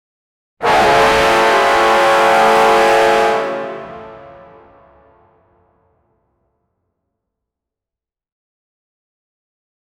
extremely-loud-fortississ-ejmusv5m.wav